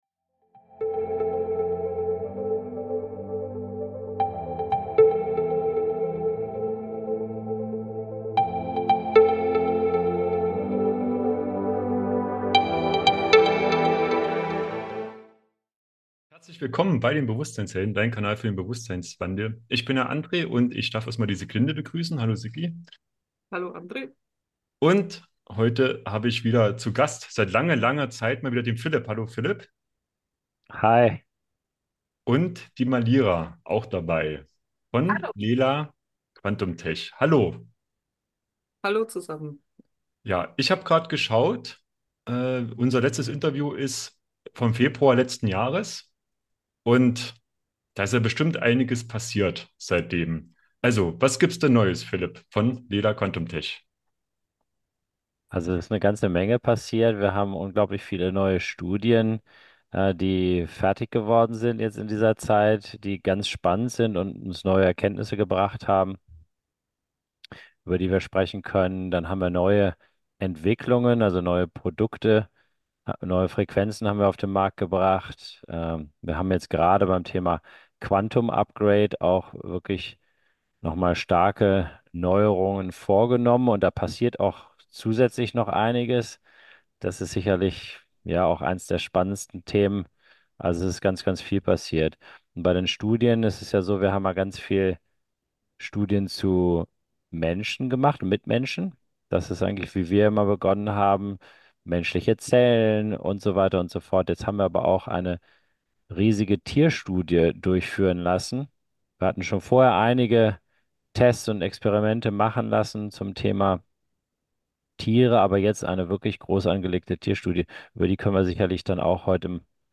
Was erwartet dich in diesem Interview? Aktuelle Studien: Gehirn EEG-Studie: Wie Handystrahlung durch Quantenenergie beeinflusst wird. Tiere und Quantenenergie: Positive Effekte auf unsere tierischen Begleiter.